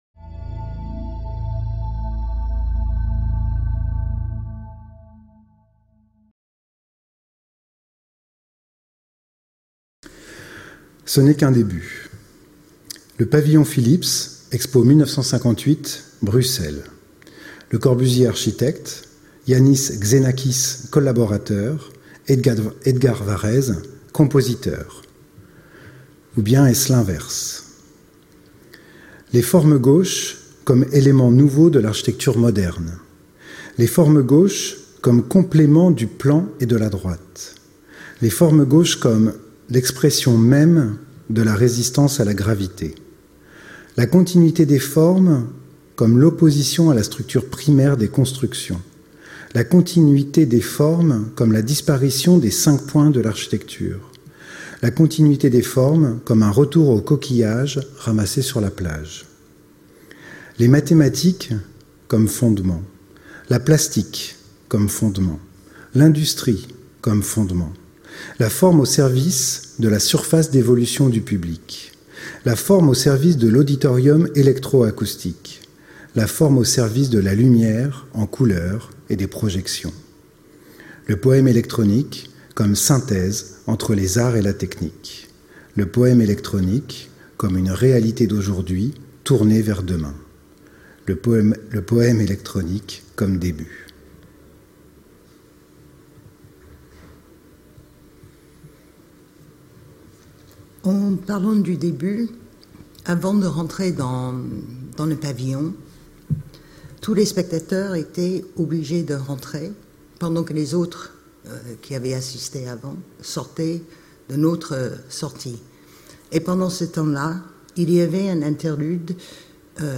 Regards croisés entre un architecte-chercheur et une musicienne-chercheuse sur une des icônes de l’architecture moderne, soixante ans plus tard : le pavillon Philips de l’expo 1958.